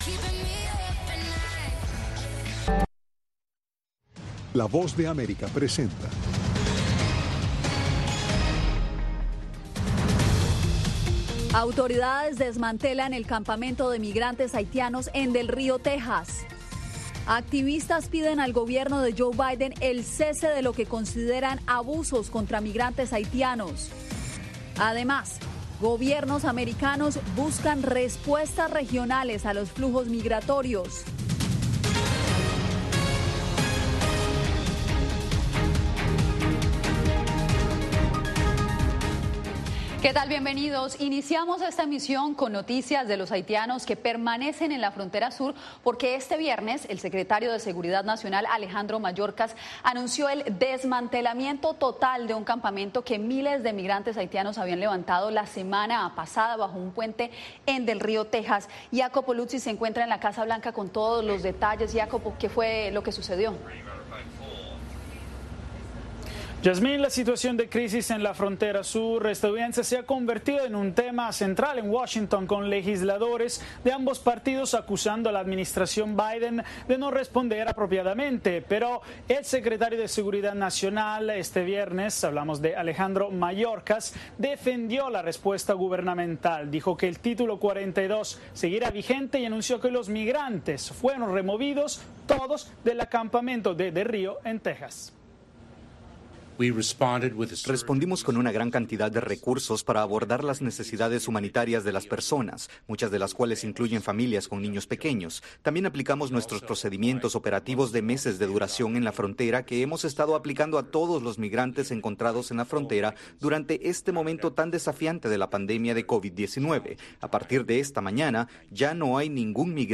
Un noticiero con información diaria de Estados Unidos y el mundo.